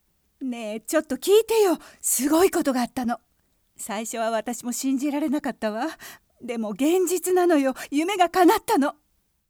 セリフ１
ボイスサンプル